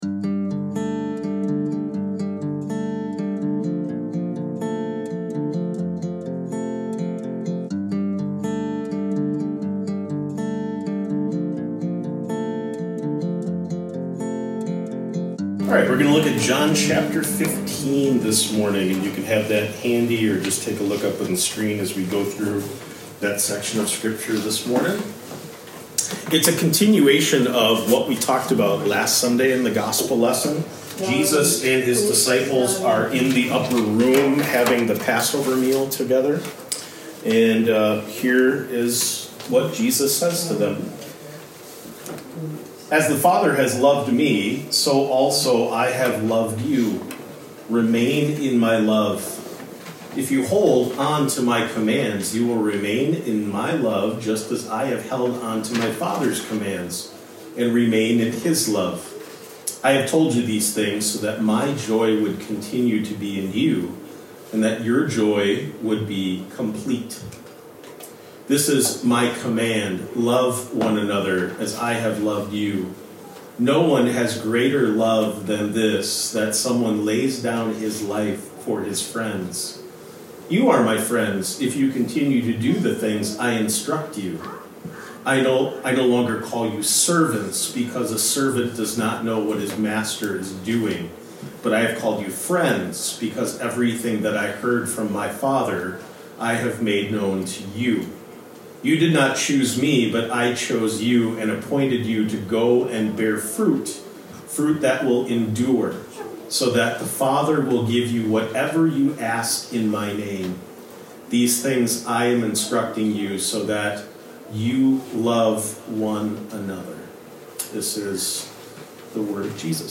Sermons | St John Evangelical Lutheran Church
Sermon begins around the 26 minute mark.